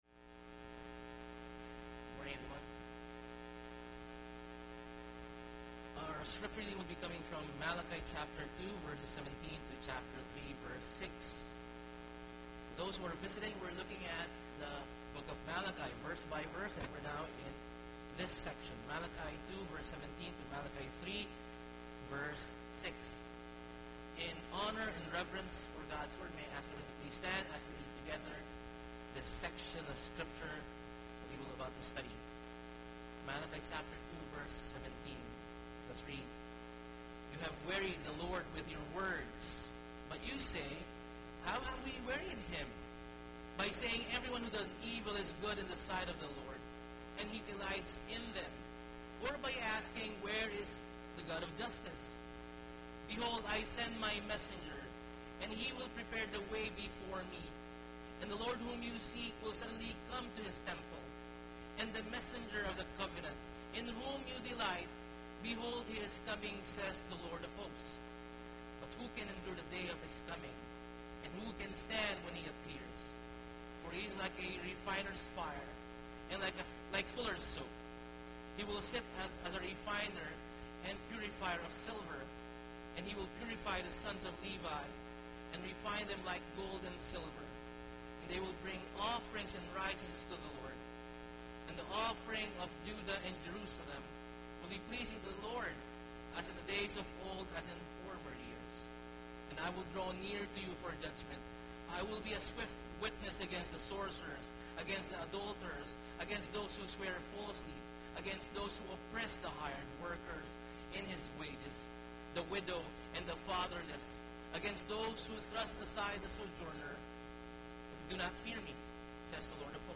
Bible Text: Malachi 2:17-3:6 | Preacher